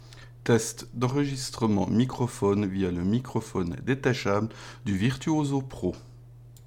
À savoir que ceux-ci ont été enregistré sans ajout d’effets audio.
Microphone détachable du Virtuoso Pro (avec et sans bonnette)
Les différences sont minimes et on notera juste un peu moins de graves et de profondeur de la voix sur le micro du Virtuoso Pro.